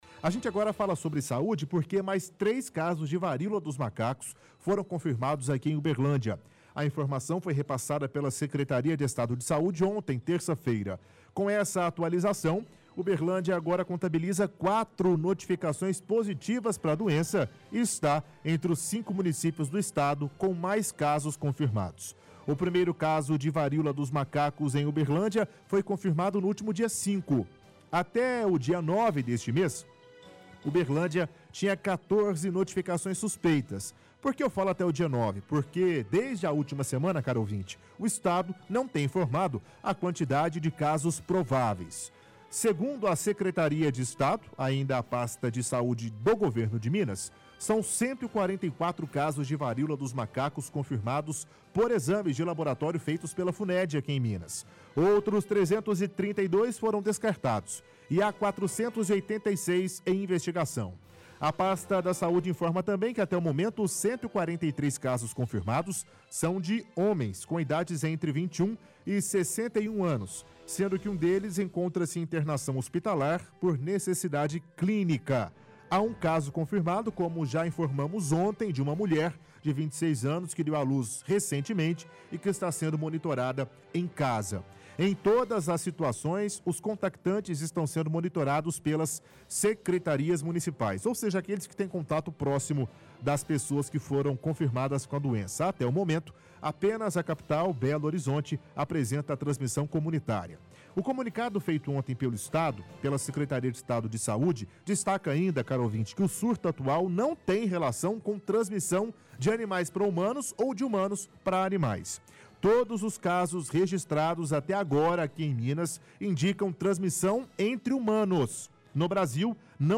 – Leitura da reportagem do Diário de Uberlândia sobre o assunto.